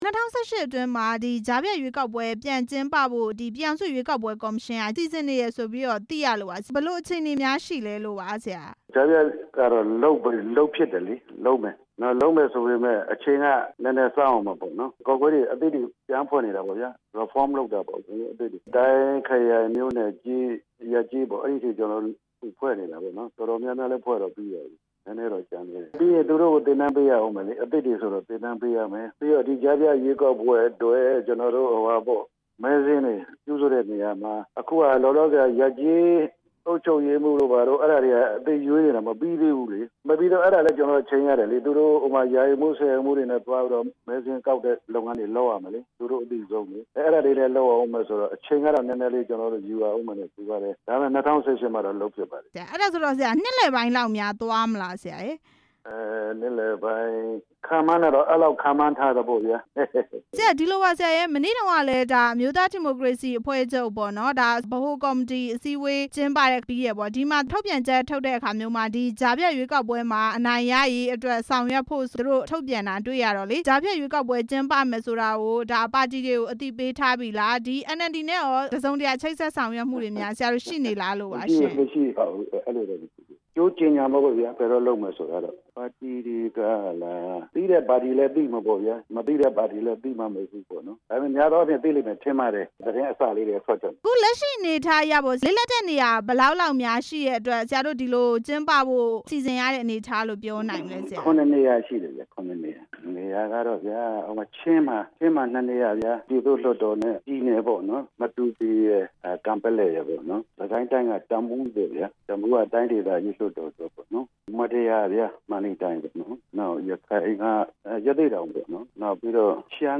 ကြားဖြတ်ရွေးကောက်ပွဲ ကျင်းပမယ့်အကြောင်း မေးမြန်းချက်